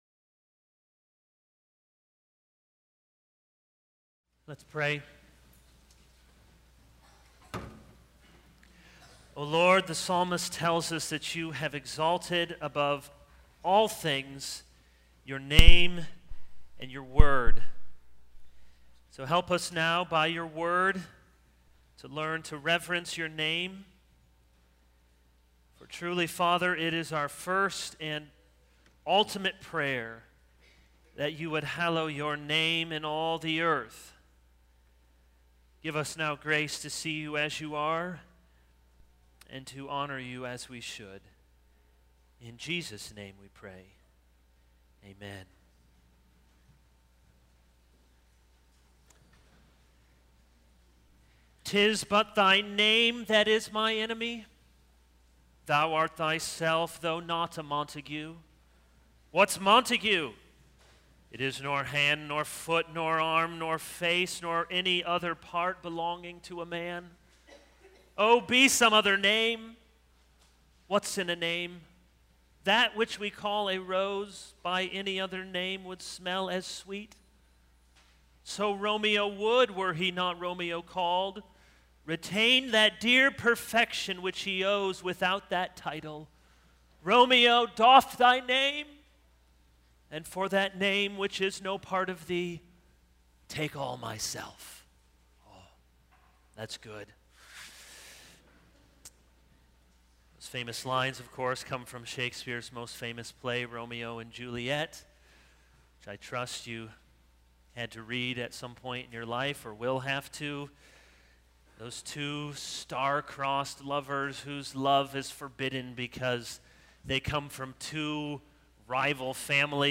All Sermons Knowing the Gospel 0:00 / Download Copied!